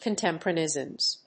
アクセント・音節con・tèm・po・rá・ne・ous・ness